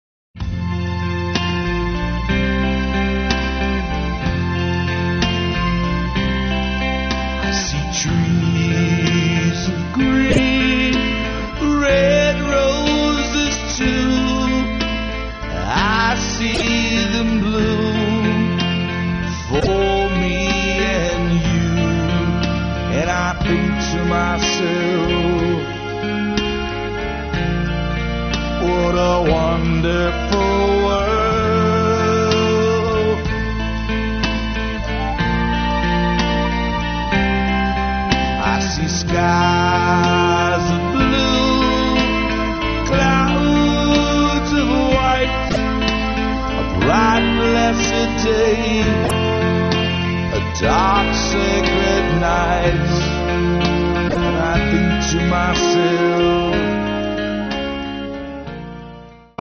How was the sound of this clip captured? We recorded it at Round Table Recording Studio in Eagle, WI.